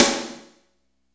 Index of /90_sSampleCDs/Roland - Rhythm Section/KIT_Drum Kits 8/KIT_Cracker Kit
SNR TV SN104.wav